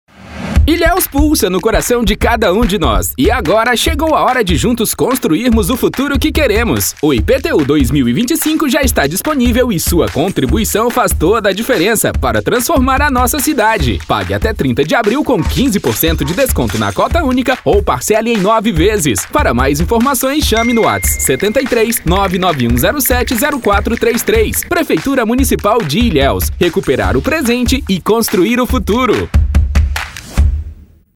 Ceará
Demo Iptu Jovem Dinâmica: